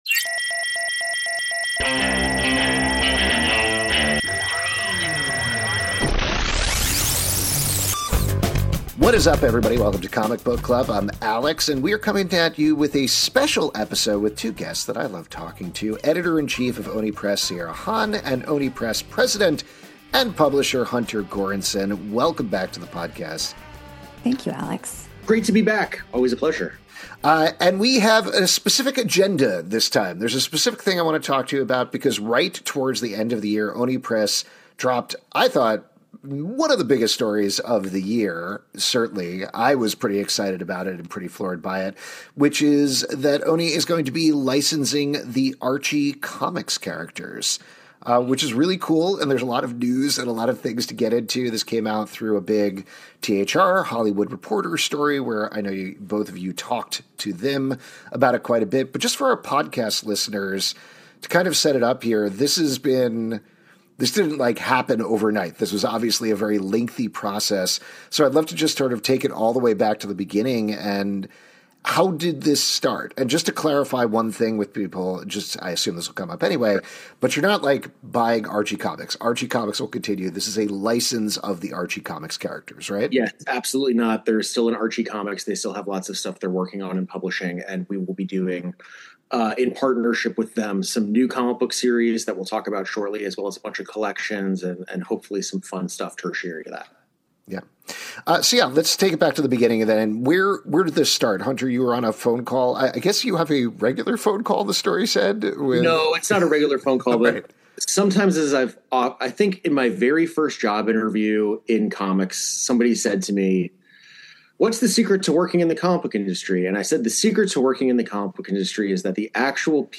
this time at Baltimore Comic Con.